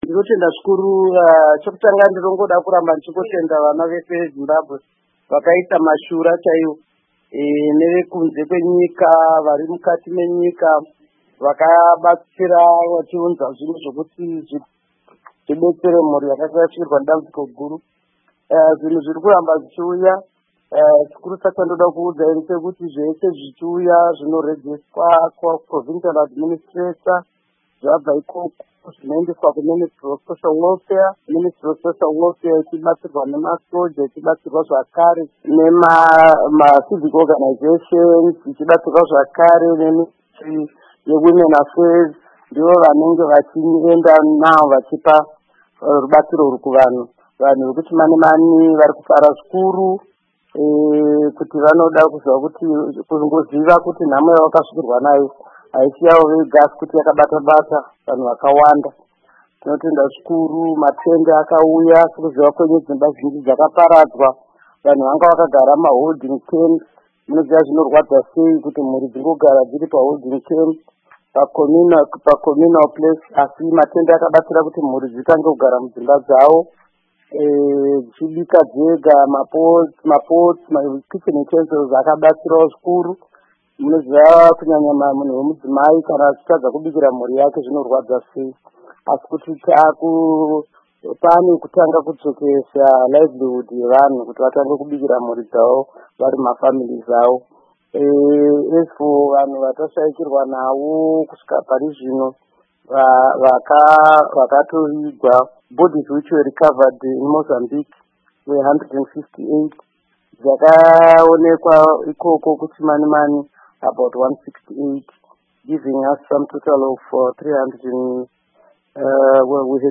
Information Minister Monica Mutsvangwa At Ministerial Briefing
Hurukuro naAmai Monica Mutsvangwa